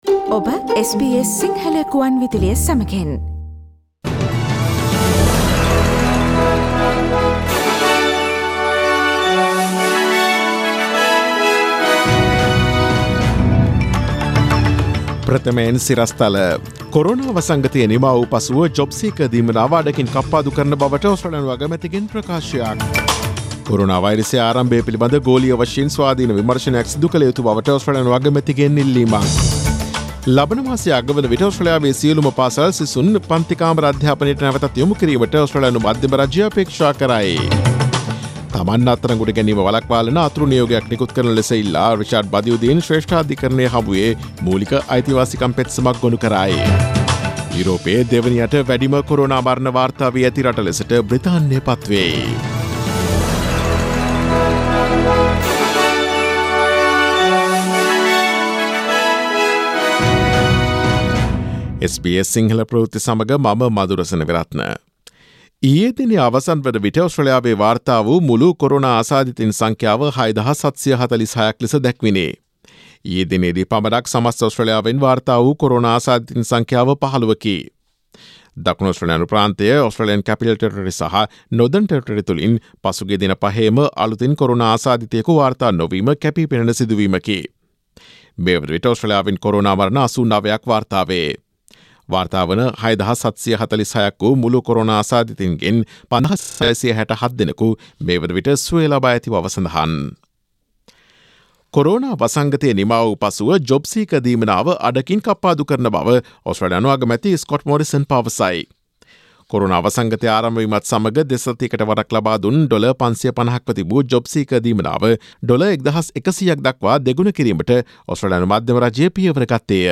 Daily News bulletin of SBS Sinhala Service: Thursday 30 April 2020